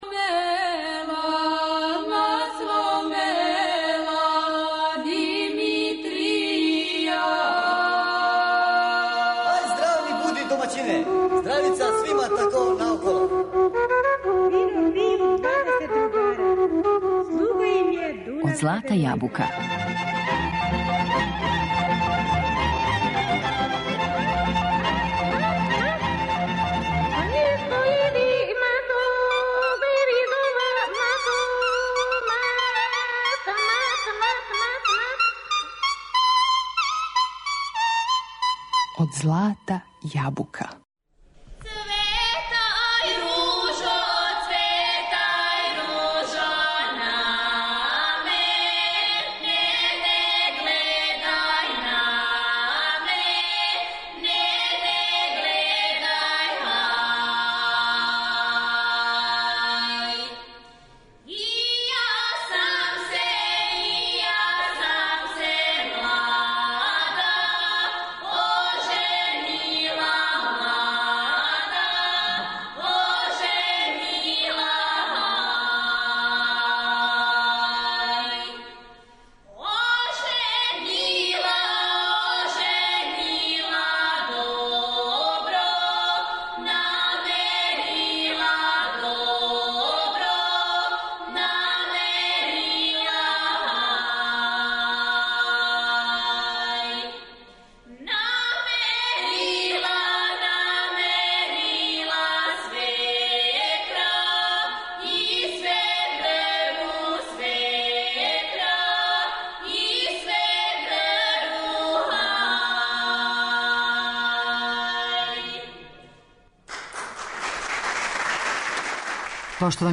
У емисији слушамо аудио записе који се налазе на ова два изузетна издања.